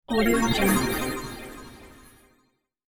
دانلود افکت صدای گرد و غبار حباب 3
افکت صدای گرد و غبار حباب 3 یک گزینه عالی برای هر پروژه ای است که به صداهای بازی و جنبه های دیگر مانند جادو، درخشش و طلایی نیاز دارد.
Sample rate 16-Bit Stereo, 44.1 kHz
Looped No